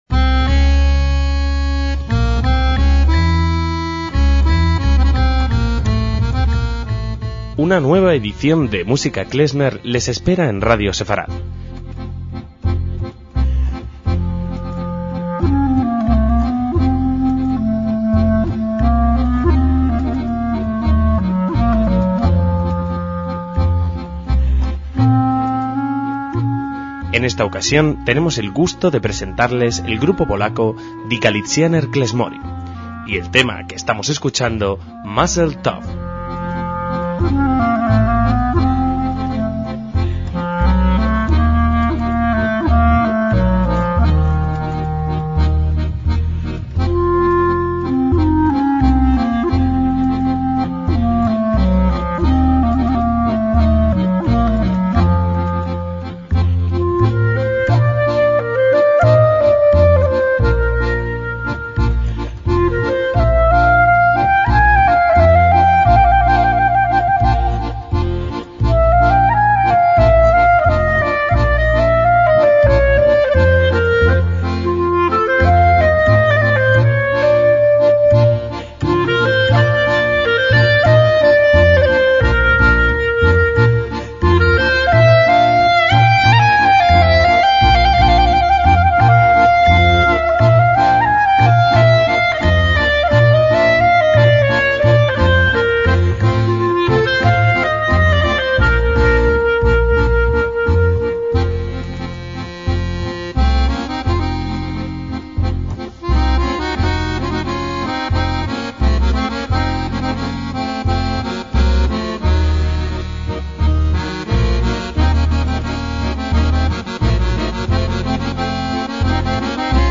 MÚSICA KLEZMER
trío polaco de música klezmer afincado en Cracovia
clarinete
acordeón
contrabajo